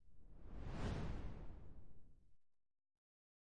嗖嗖嗖" 嗖嗖嗖长的低
描述：一个简单的飞快的效果。长而低。
Tag: 旋风 飞通过 空气 快速 阵风 传递通过 沙沙